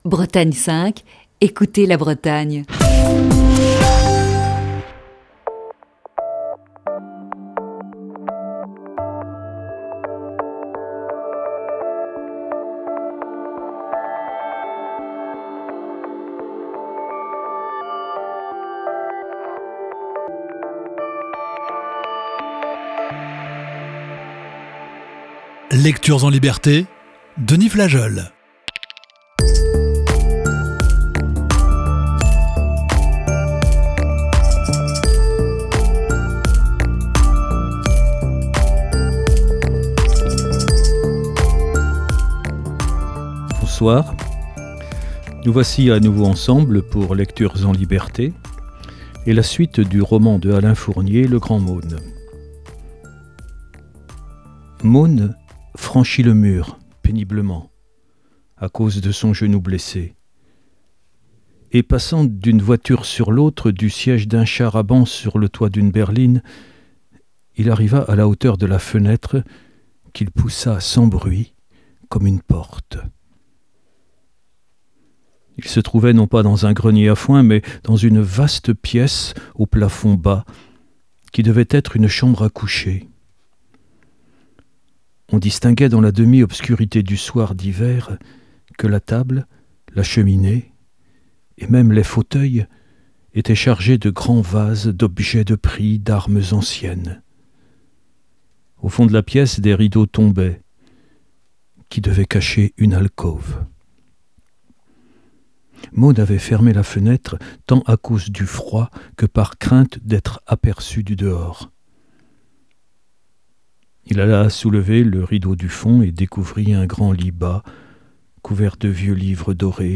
Émission du 14 août 2020. Pause estivale pour Lecture(s) en liberté.